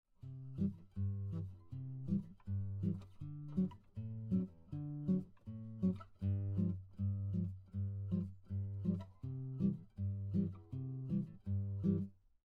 This fingerpicking style is characterized by a repeated bass pattern, while playing chords and melody at the same time.
For the next step, we are adding chords between the bass notes.
Although you could use fingers 2, 3, and 4, you get a unique brushing effect with your thumb.
Travis picking pattern 2: Alternating bass notes and chords